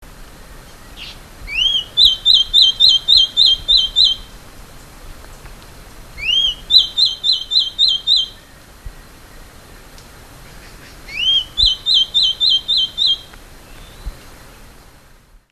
Balança-Rabo-de-Máscara (Polioptiladumicola)
O canto é suave, mas na época reprodutiva fica mais alto e variado, podendo até lembrar o de outras aves ou parecer uma risada.